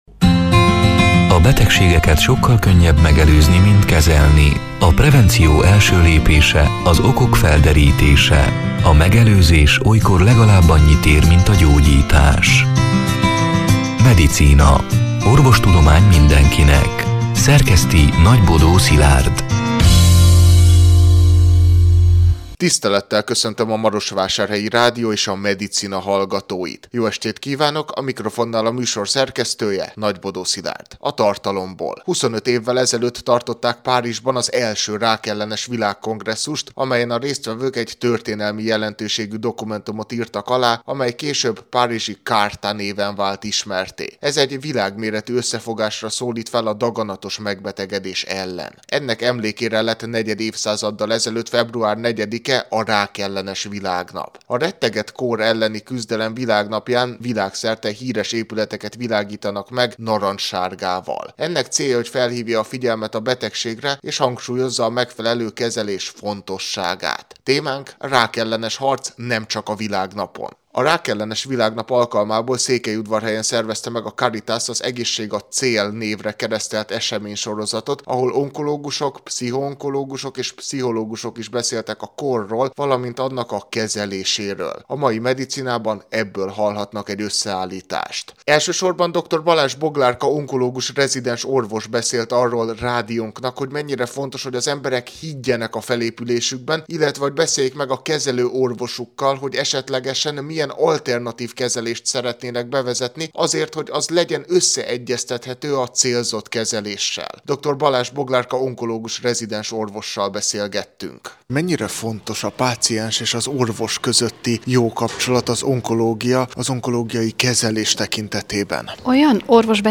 A rákellenes világnap alkalmából, február negyedikén, Székelyudvarhelyhelyen szervezte meg a Caritas az EGÉSZség a cél eseménysorozatot, ahol onkológusok, pszichoonkológusok és pszichológusok is beszéltek a kórról, valamint annak kezeléséről. A soron következő Medicinában ebből hallhatnak egy összeállítást.